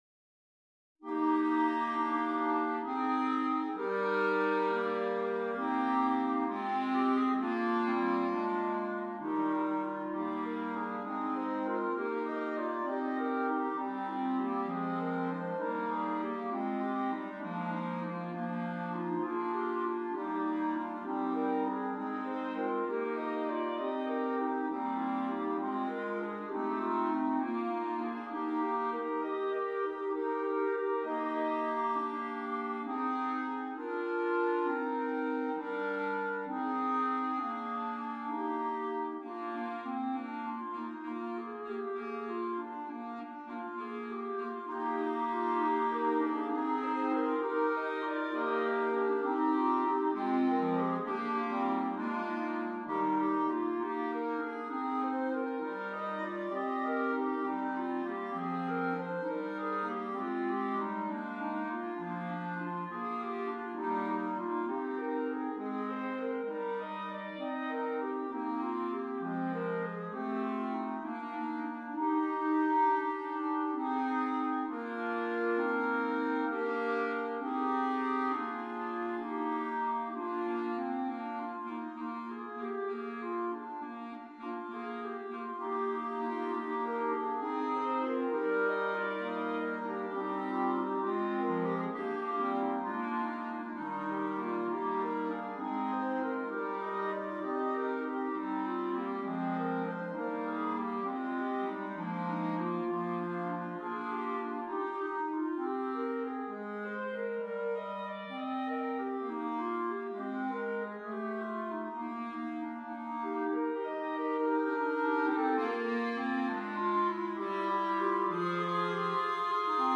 6 Clarinets